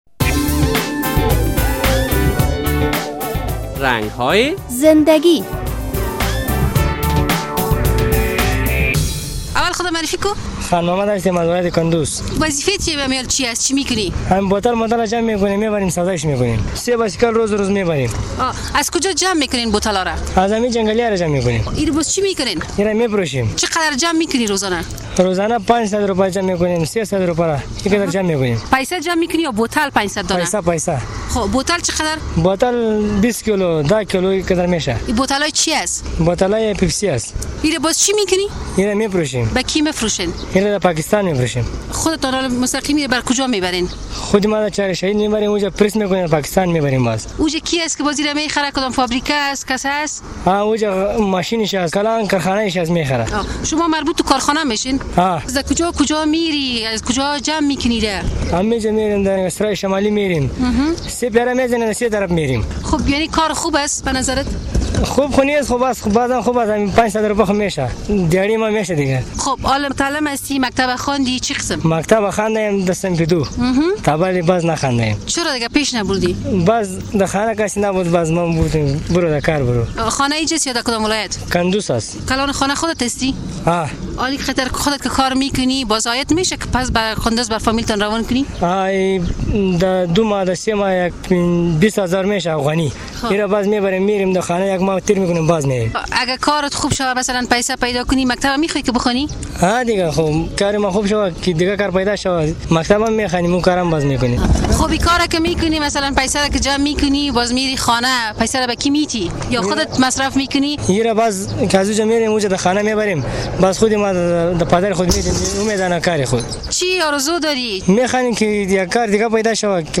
فقر و تنگدستی افغان ها را به کار های شاقه کشانده است، حتی آنها به جمع آوری پلاستیک و استخوان، شیشه و بوتل های آب از کنار سرک ها و ذباله دانی ها و جوی های آب رو آورده اند. در این برنامه با یک تن از جمع کننده بوتل های آب مصاحبه کرده ایم از عایداتش می گوید: